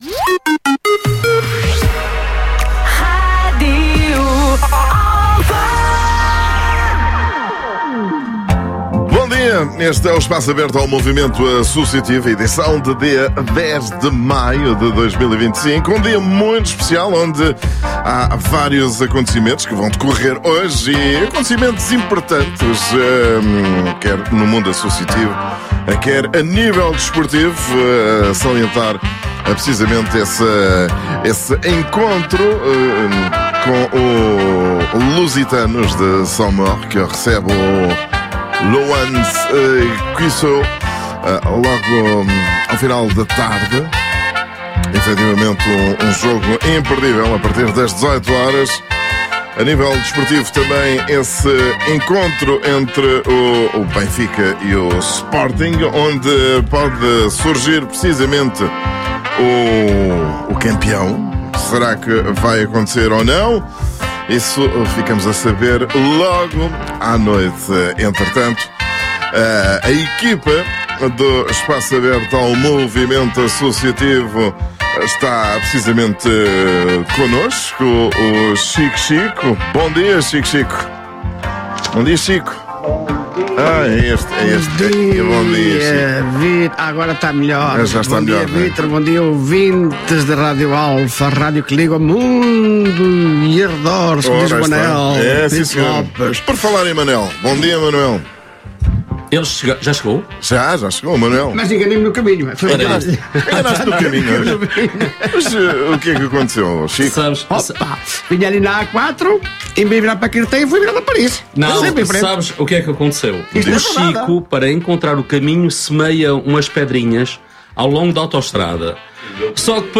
Todas as semanas a equipa do Espaço Aberto recebe em estúdio as associações lusófonas para a divulgação das actividades associativas.